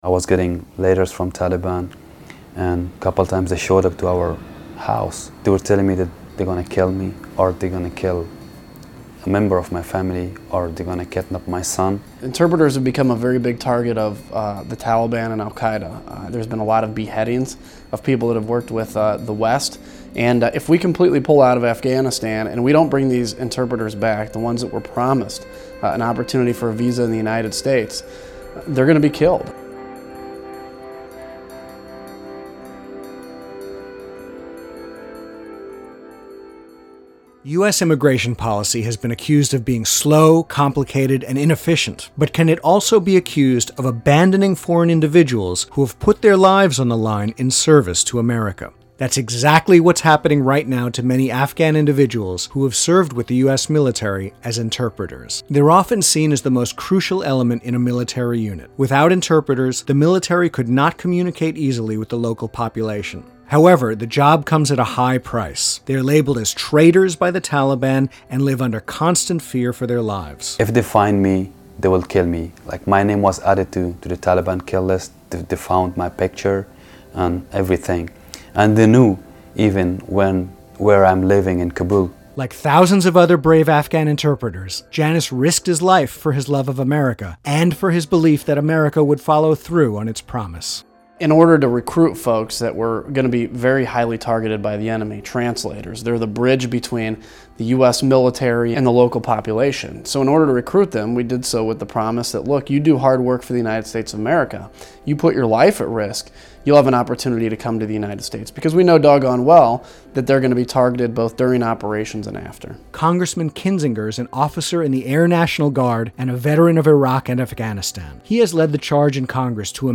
"Interpreters have become a very big target of the Taliban and Al Queda," says Rep. Adam Kinzinger (R-Ill.).
Music by The Abbasi Brothers.